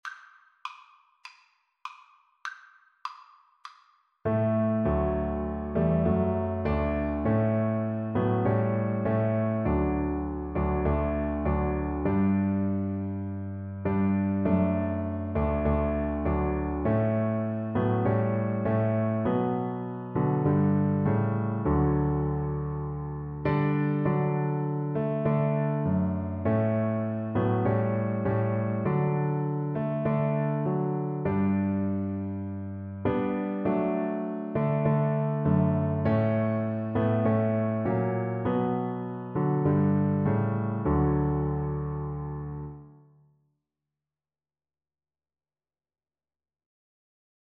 4/4 (View more 4/4 Music)
Scottish